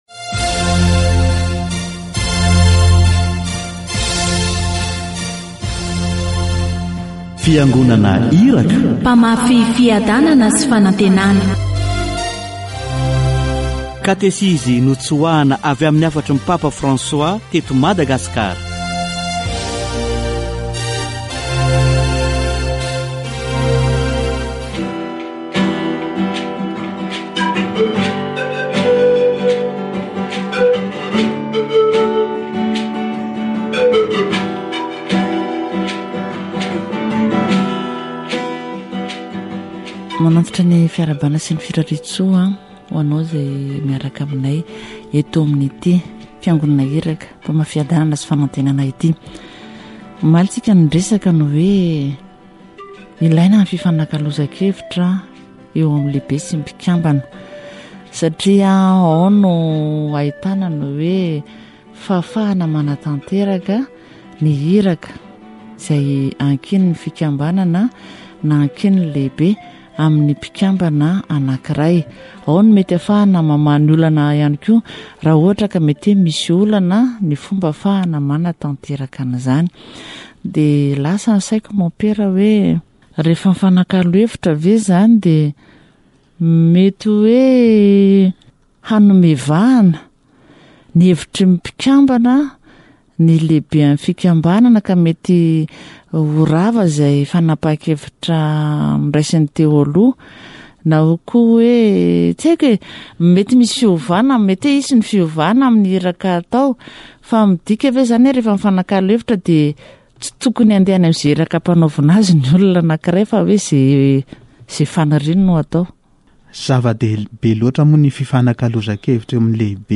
Katesizy momba ny fanekena